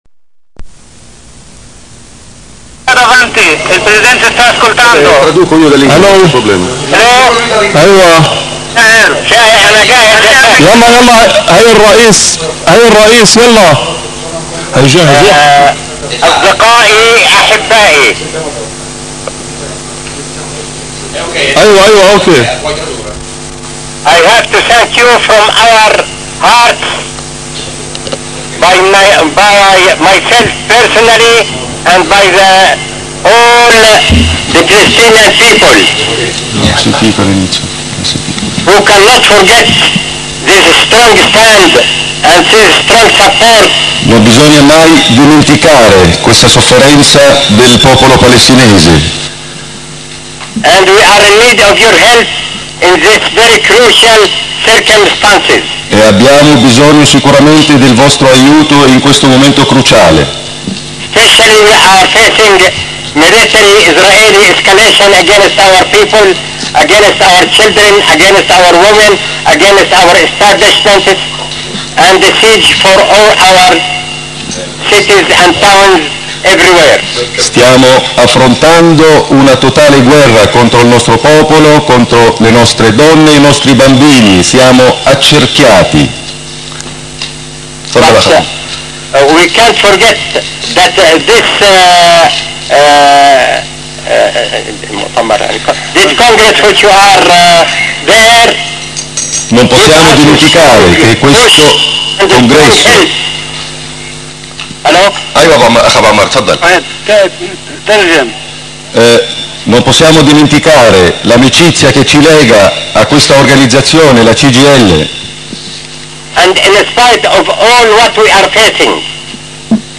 telefonata del Presidente Yasser  Arafat in diretta al 7° Congresso Fp Cgil